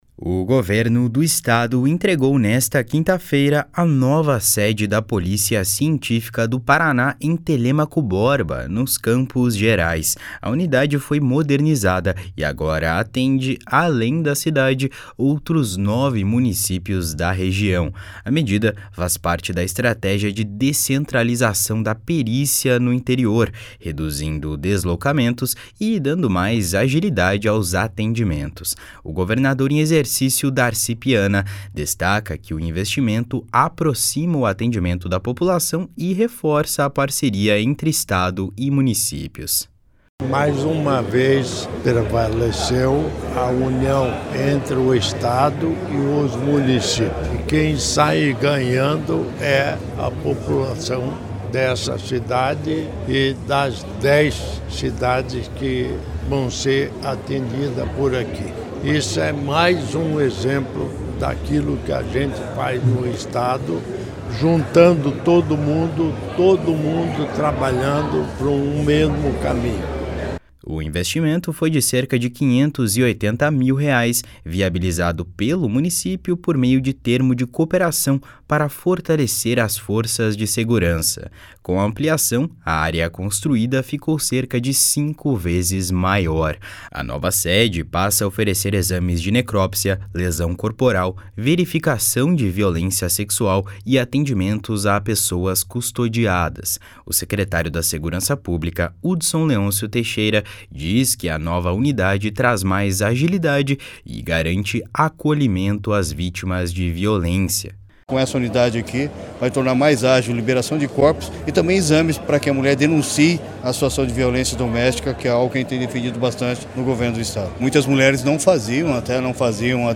O governador em exercício, Darci Piana, destaca que o investimento aproxima o atendimento da população e reforça a parceria entre Estado e municípios.
A nova sede passa a oferecer exames de necropsia, lesão corporal, verificação de violência sexual e atendimentos a pessoas custodiadas. O secretário da Segurança Pública, Hudson Leôncio Teixeira, diz que a nova unidade traz mais agilidade e garante acolhimento às vítimas de violência.